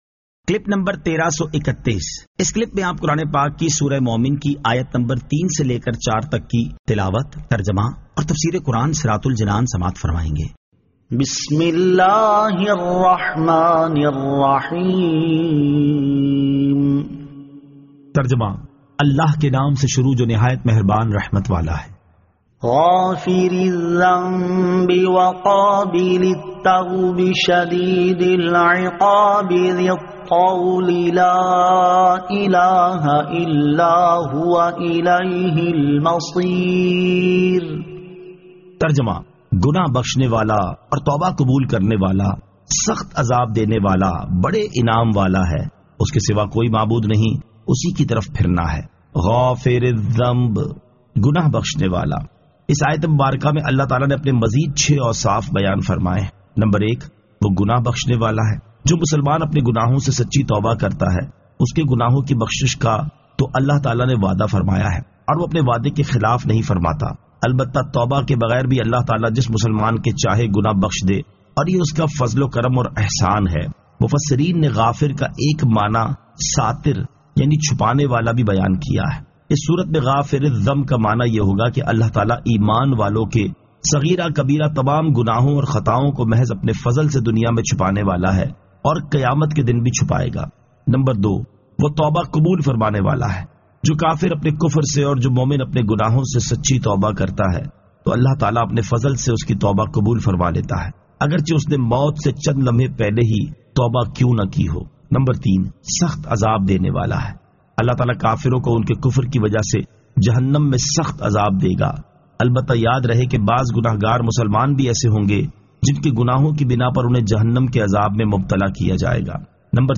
Surah Al-Mu'min 03 To 04 Tilawat , Tarjama , Tafseer